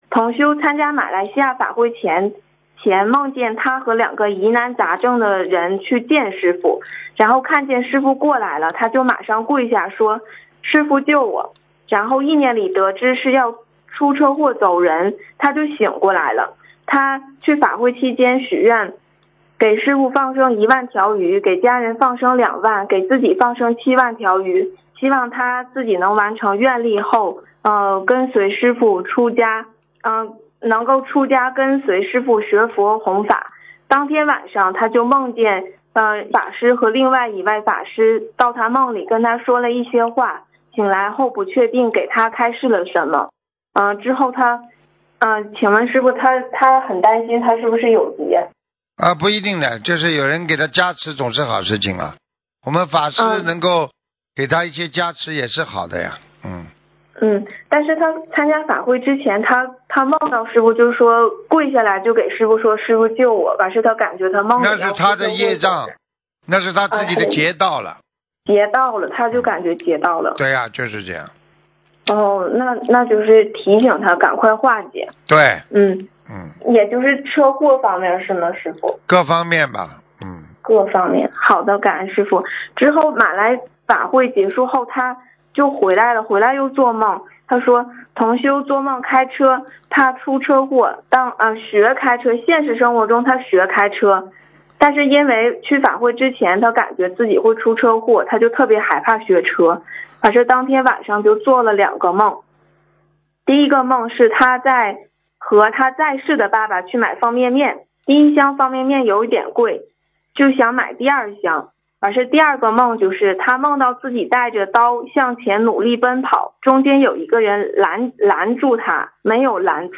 目录：☞ 剪辑电台节目录音_集锦